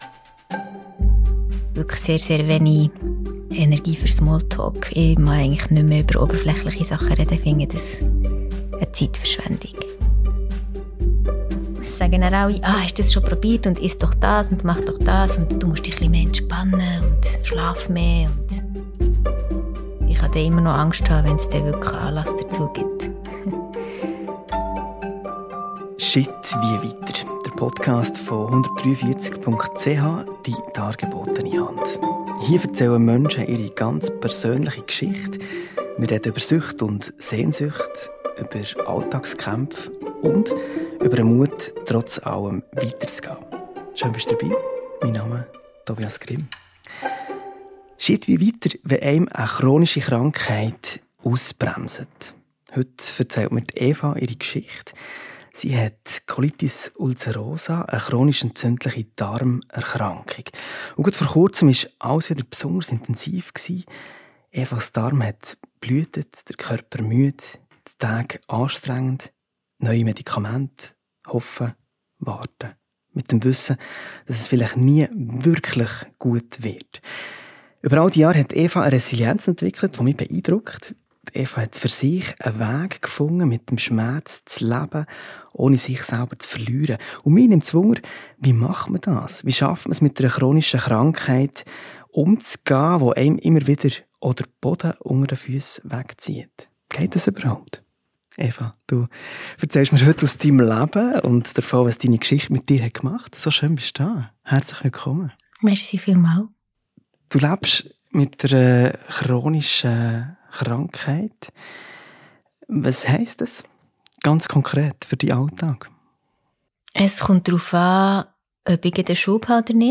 Ein ehrliches, ruhiges Gespräch über das Weitergehen in einem anderen Tempo.